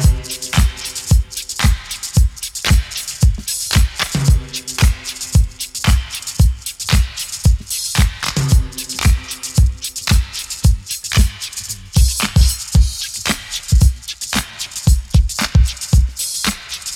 • 113 Bpm Modern Drum Loop Sample C Key.wav
Free breakbeat sample - kick tuned to the C note. Loudest frequency: 2625Hz
113-bpm-modern-drum-loop-sample-c-key-Wkk.wav